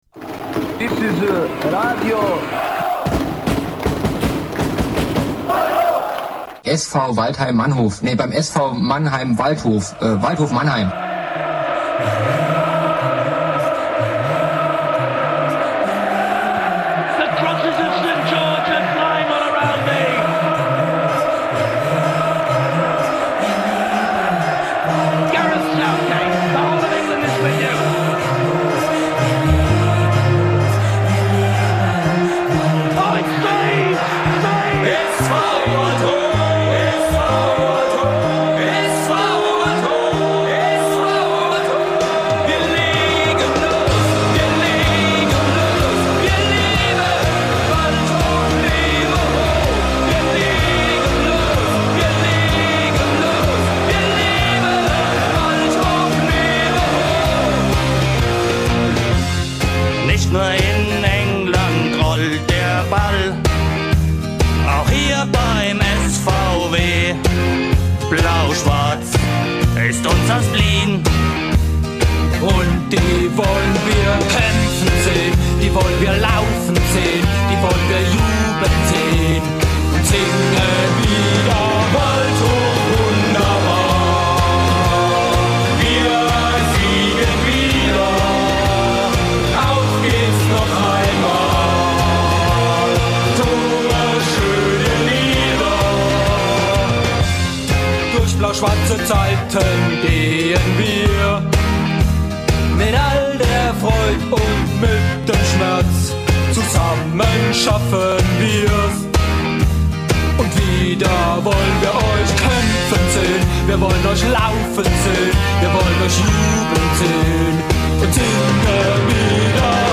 Eine ganze Stunde lang stellten sich die beiden „Livestream“-Macher beim SV Waldhof Mannheim 07 dabei live im bermuda.funk-Studio 1 unseren und Euren Fragen.